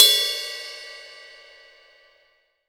Index of /90_sSampleCDs/AKAI S-Series CD-ROM Sound Library VOL-3/DRY KIT#3